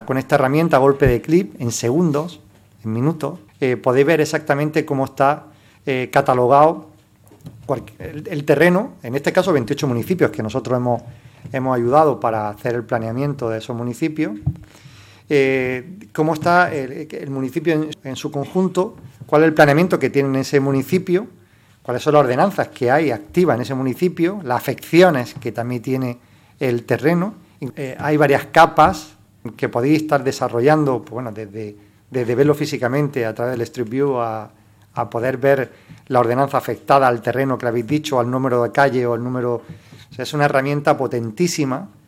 El presidente, Javier A. García, destaca la importancia de esta acción que pone la información geográfica al alcance de todas las personas y los profesionales